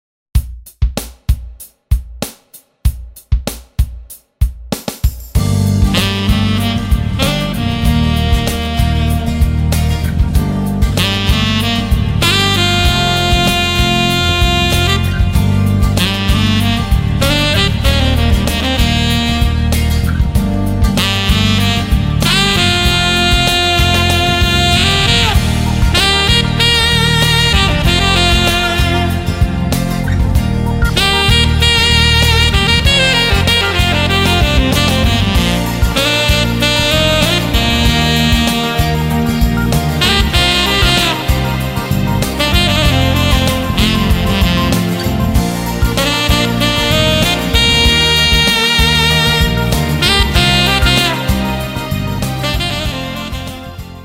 (SOPRANO, ALTO & TENOR SAXES)
(BASS)
(ACUSTIC & ELECTRIC GUITARS)
DRUM& PERCUSSION PROGRAMMING)